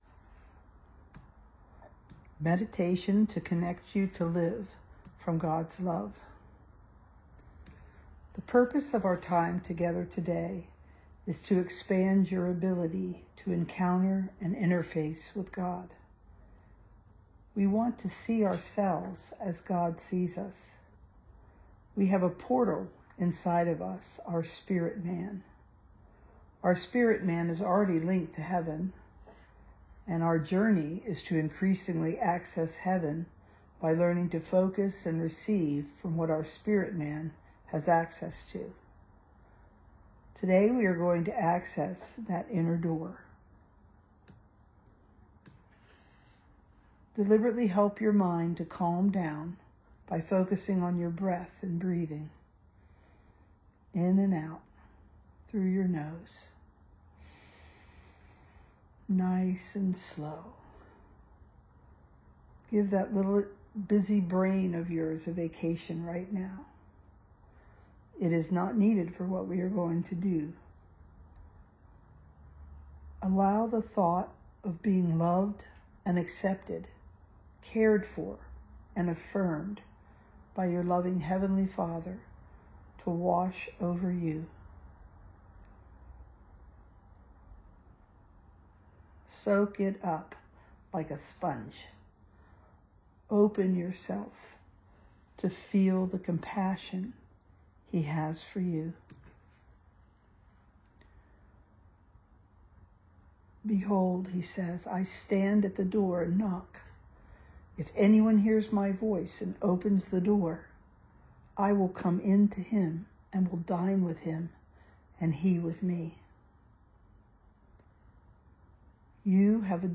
Meditation to Connect You to Live from God's Love